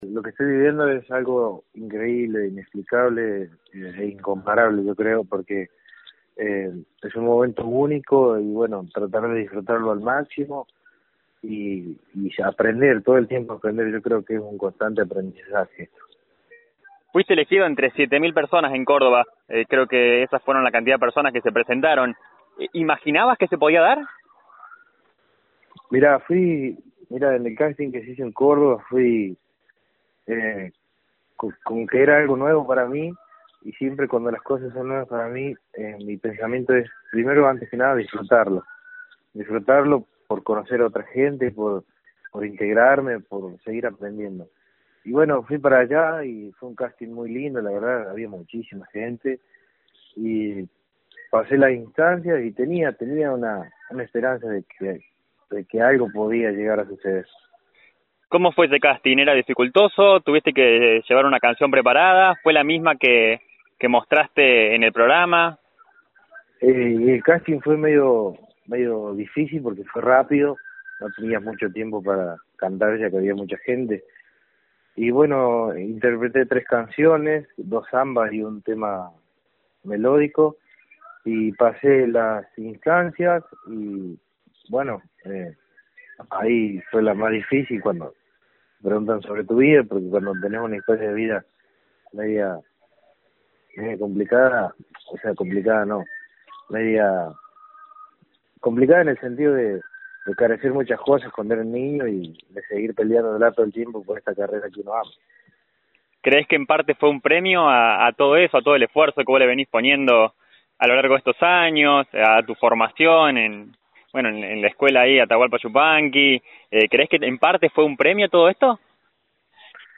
En una entrevista que brindó a FM Samba